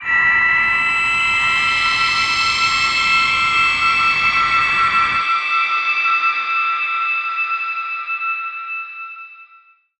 G_Crystal-E8-mf.wav